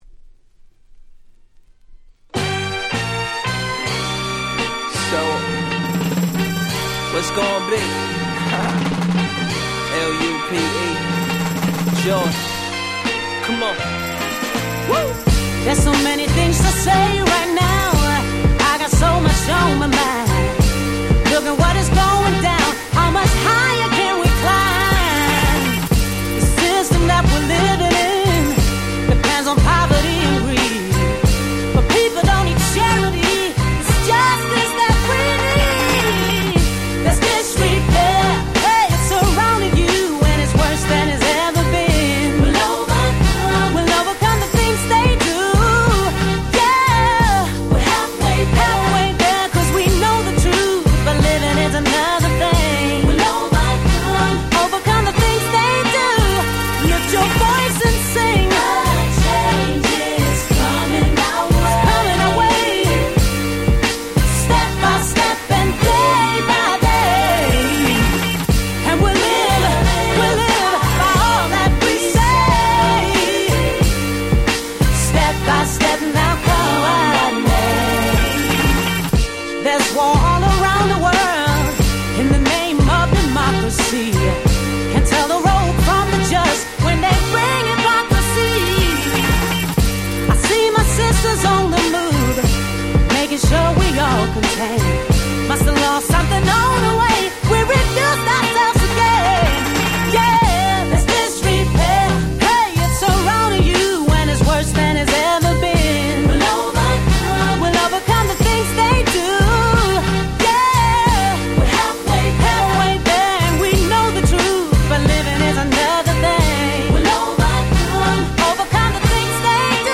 06' Super Nice Neo Soul / R&B !!